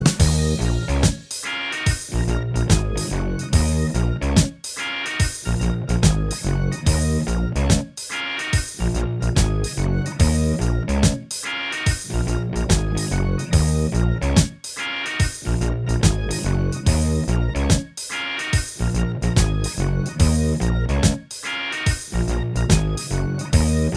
Minus All Guitars Pop (1980s) 4:01 Buy £1.50